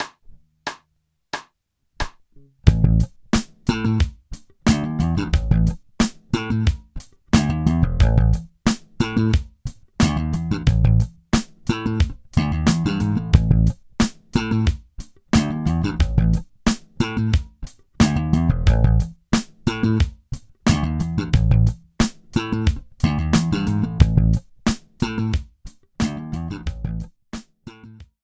Groove Construction 1 Bass Groove Construction 1